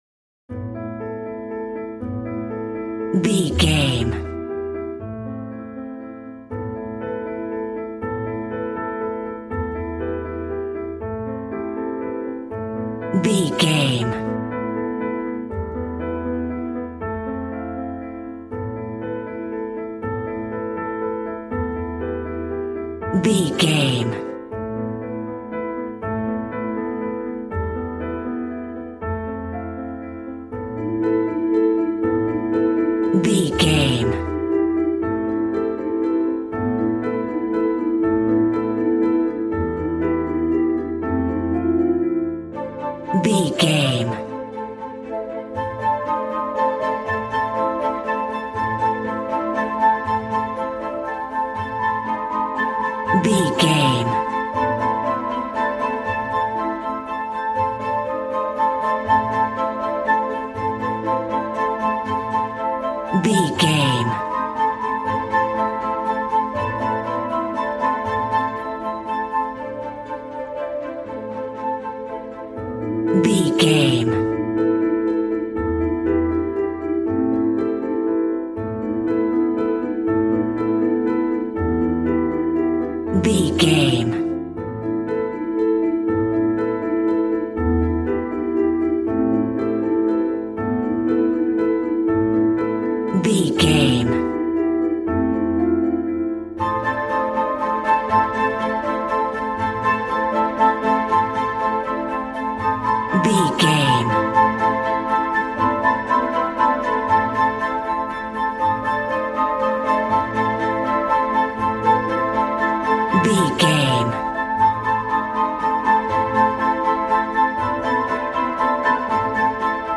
Regal and romantic, a classy piece of classical music.
Aeolian/Minor
regal
strings
violin
brass